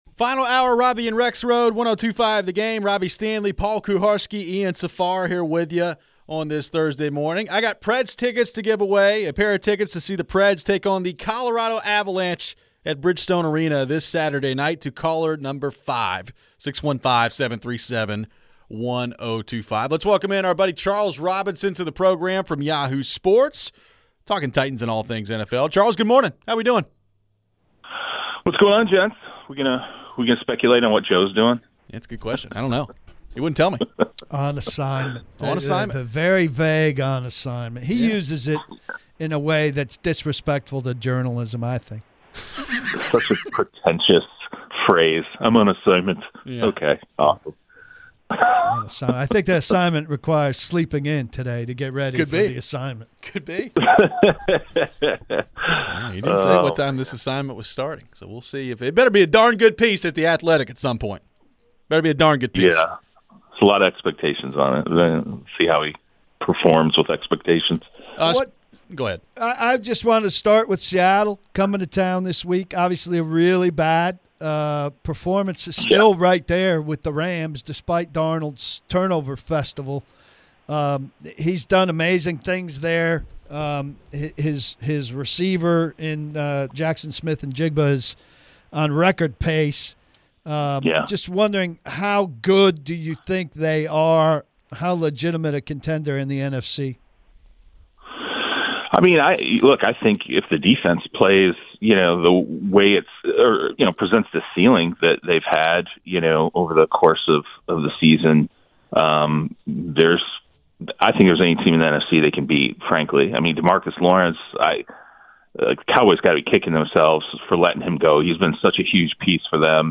We wrap up the show with your phones.